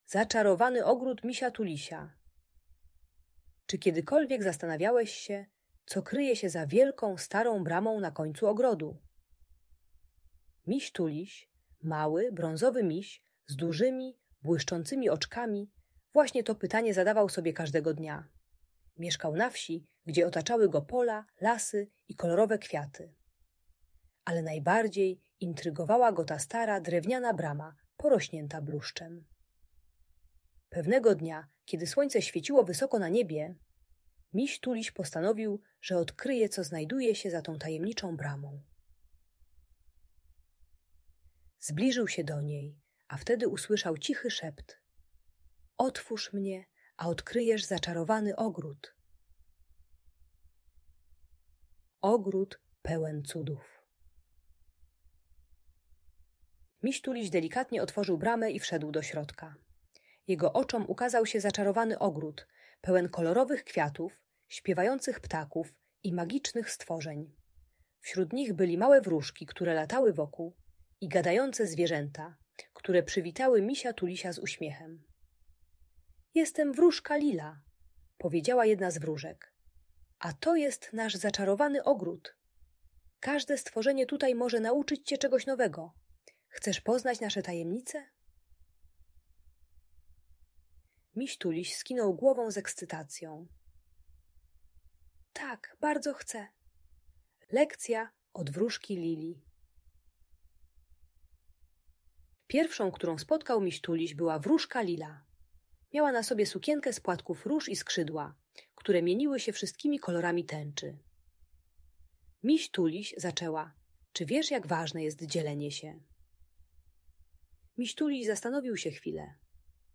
Zaczarowany ogród Misia Tulisia - magiczna historia - Audiobajka